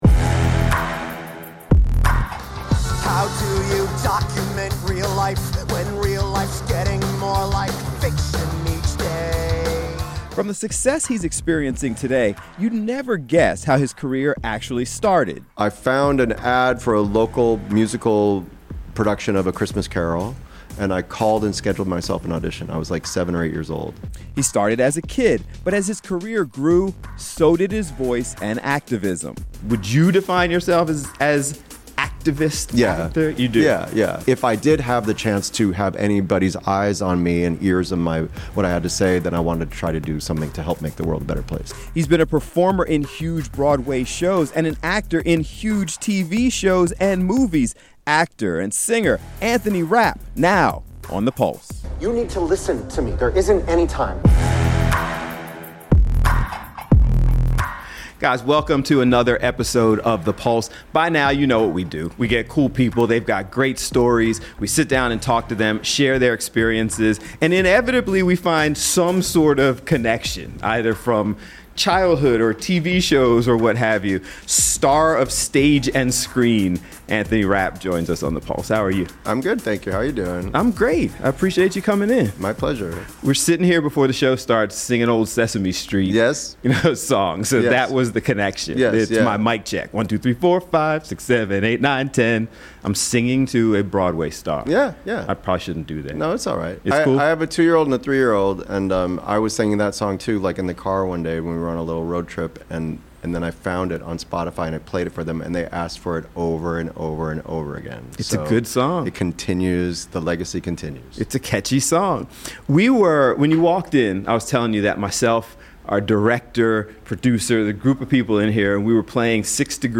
in-depth interviews with celebrities, people in the news, and more